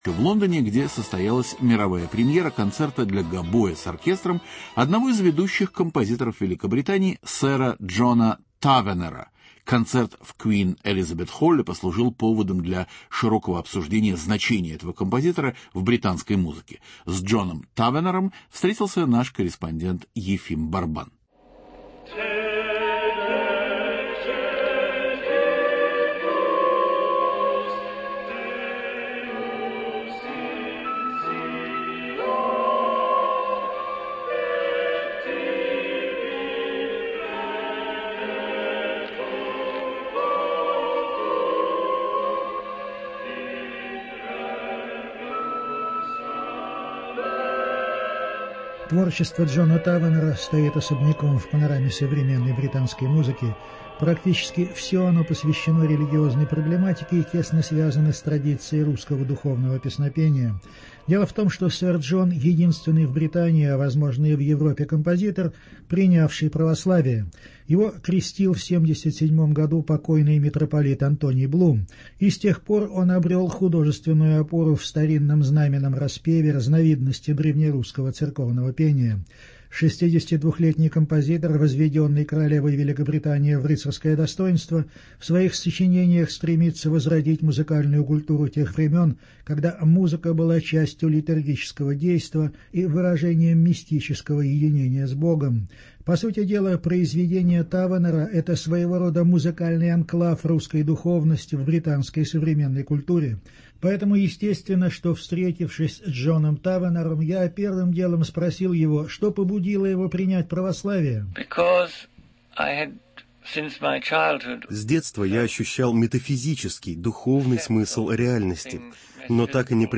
Сэр Джон Тавенер- интервью Радио свобода.